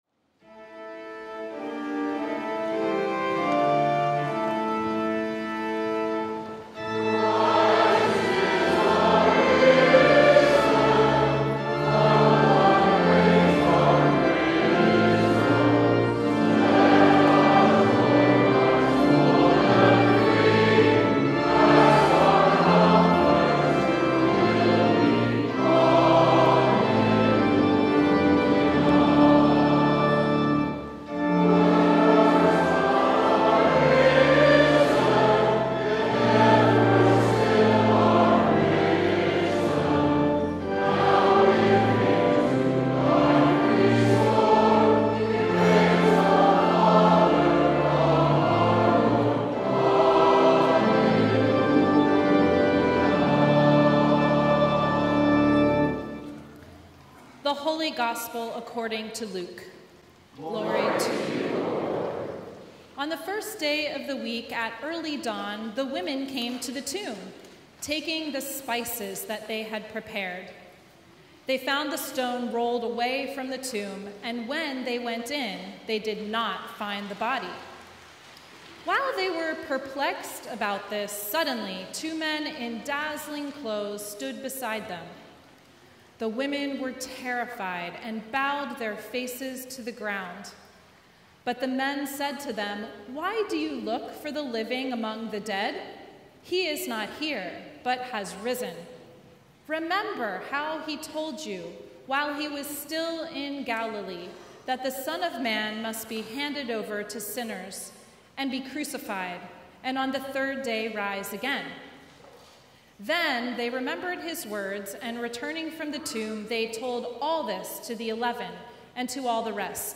Sermon from Easter Sunday 2025